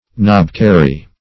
knobkerrie \knob"ker`rie\, knobkerry \knob"ker`ry\, n. [Boer D.